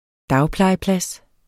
Udtale [ ˈdɑwplɑjə- ]